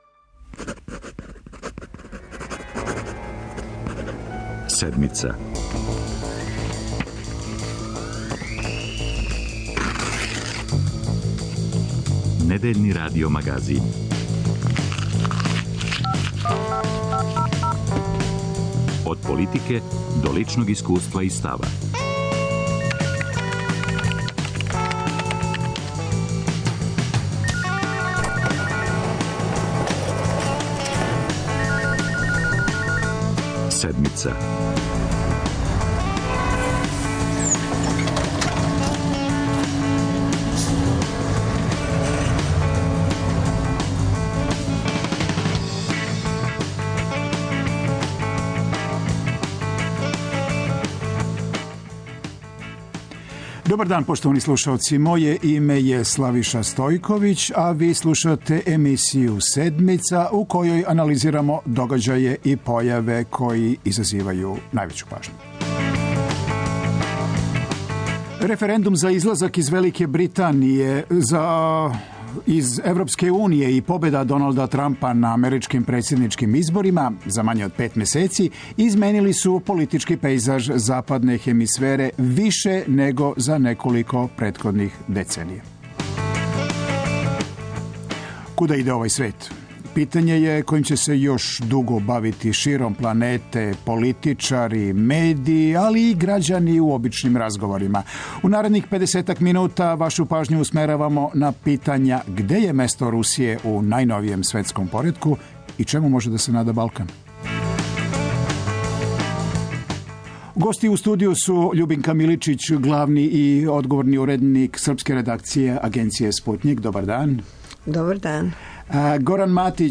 Укључићемо и аналитичаре из Русије и Немачке.